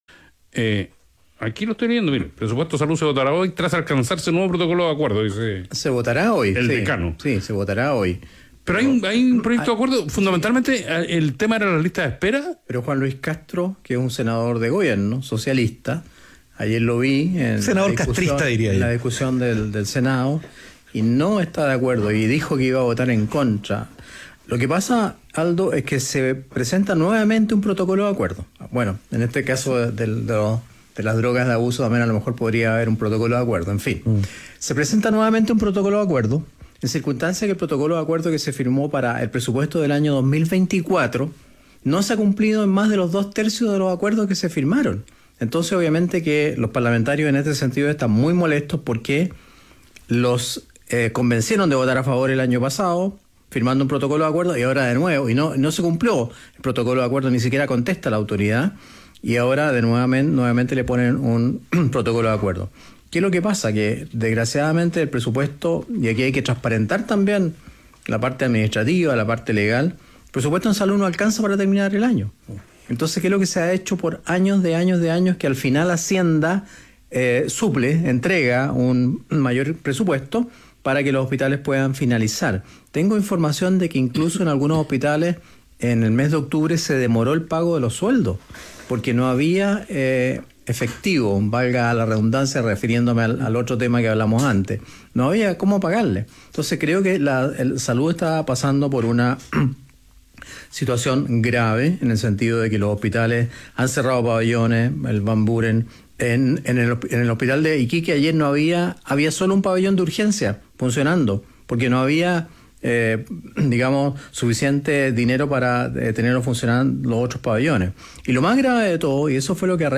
En conversación con País ADN, el exministro arremetió contra el Ejecutivo, en el marco de la discusión por el presupuesto de salud.